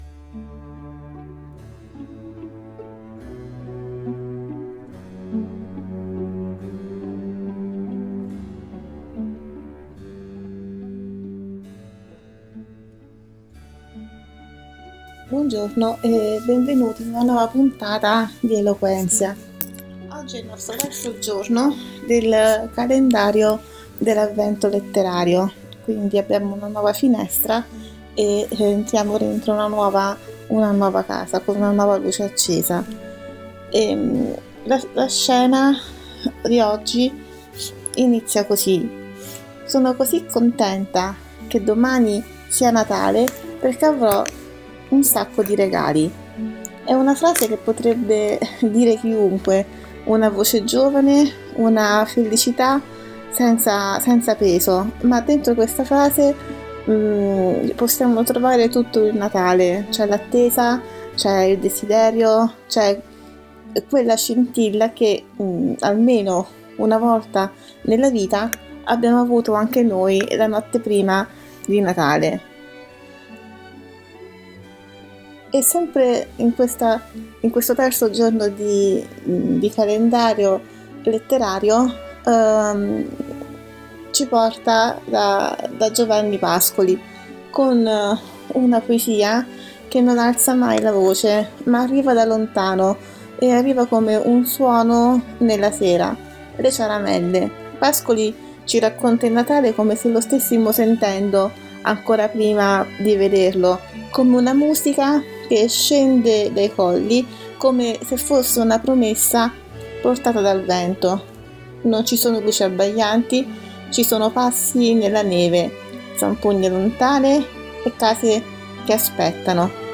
La nostra terza finestra si apre con una voce impaziente e felice:
Oggi il nostro Natale letterario prende forma con Giovanni Pascoli e le sue Ciaramelle, dove il Natale arriva come un suono lontano, tra zampogne nella neve e promesse d’inverno, prosegue poi con Louisa May Alcott e Il Natale di Tilly, tra attese semplici e gioie luminose. Ad accompagnarci, la dolce geometria di Canone in Re maggiore di Johann Pachebel.